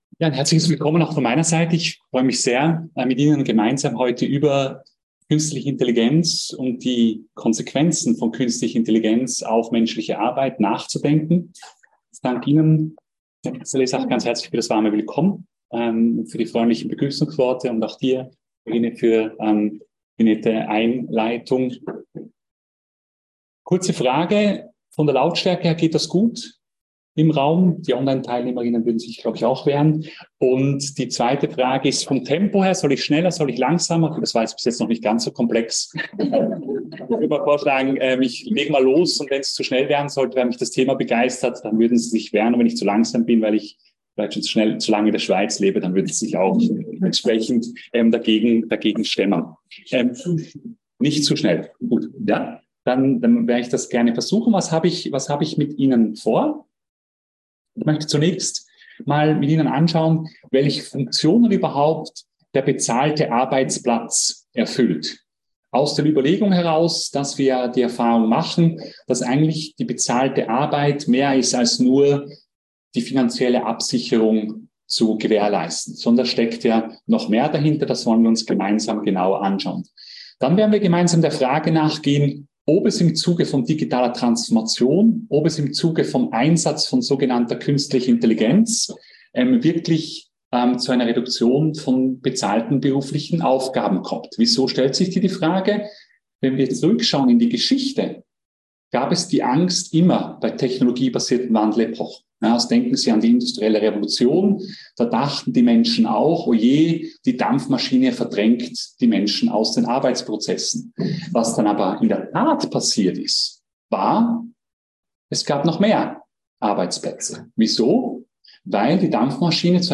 K_nstliche_Intelligenz_Vortrag_mp3